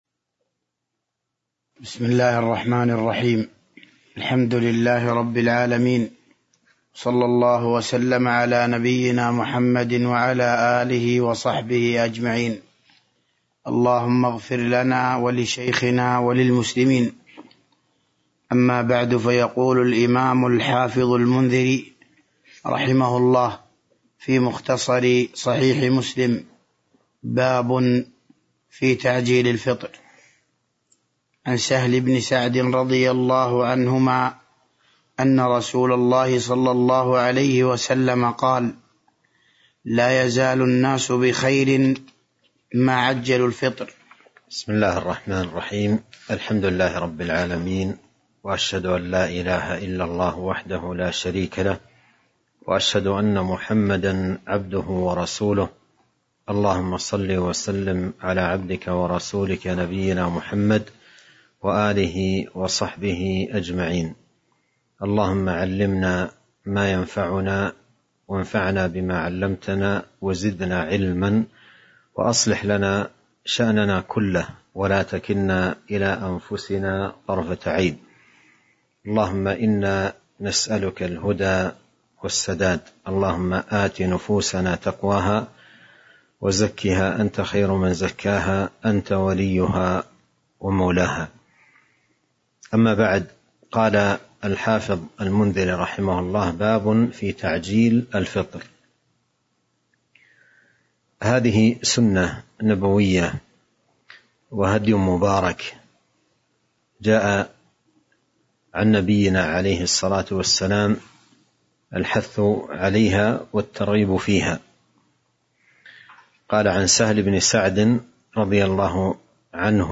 تاريخ النشر ١٠ شعبان ١٤٤٢ هـ المكان: المسجد النبوي الشيخ